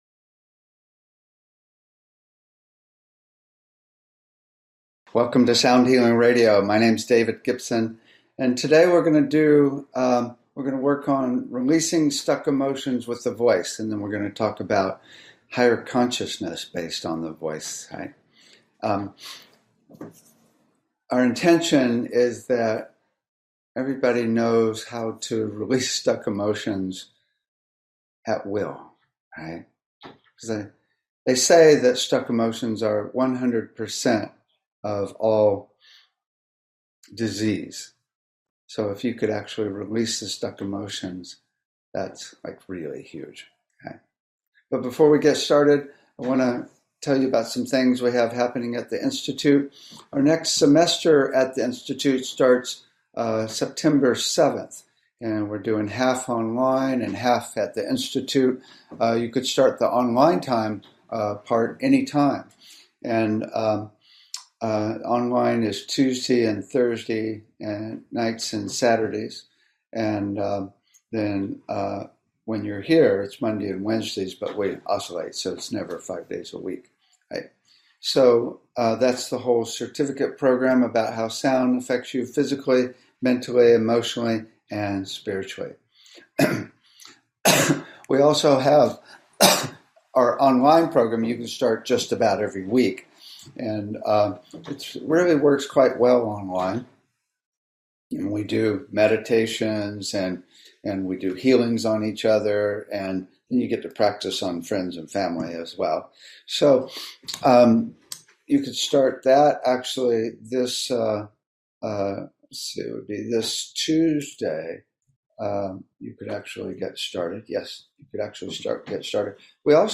Talk Show Episode, Audio Podcast, Sound Healing and Releasing Stuck Emotions with the Voice on , show guests , about Releasing Stuck Emotions with the Voice, categorized as Health & Lifestyle,Sound Healing,Physics & Metaphysics,Emotional Health and Freedom,Personal Development,Science,Self Help,Spiritual,Technology